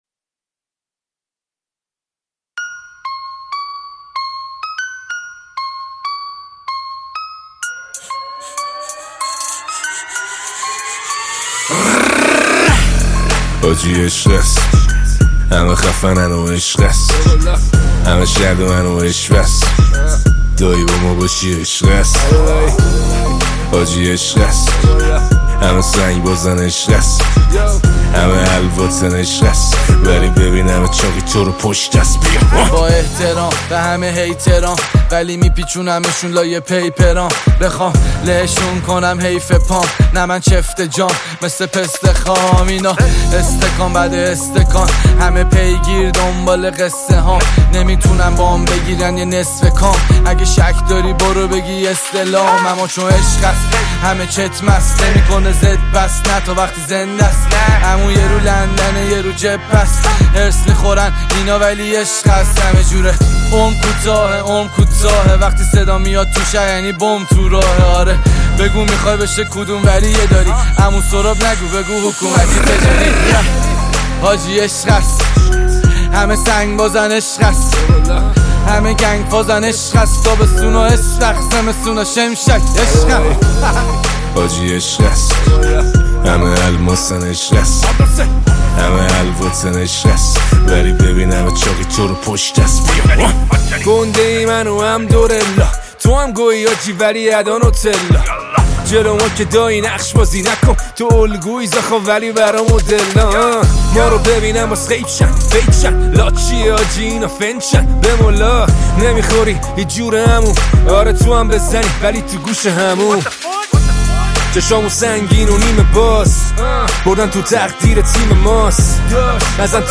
دانلود آهنگ های جدید رپ فارسی های جدید